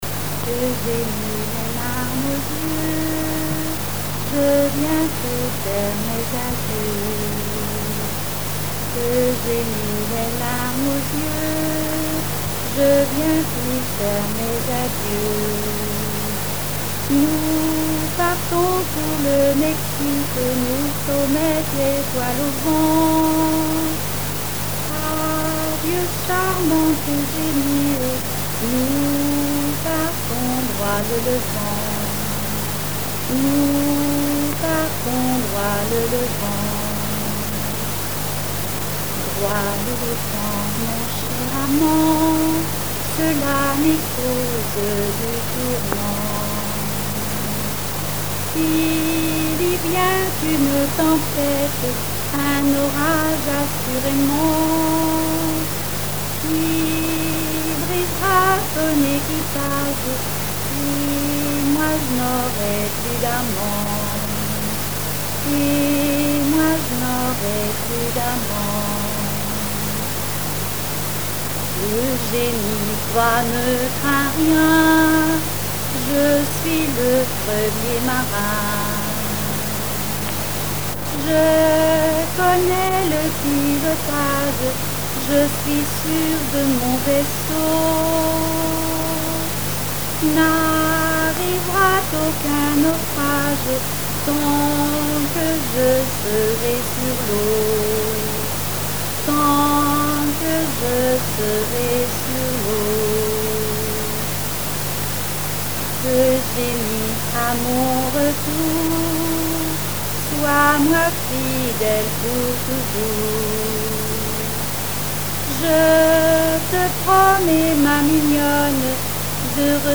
Genre strophique
répertoire de chansons populaire et traditionnelles
Pièce musicale inédite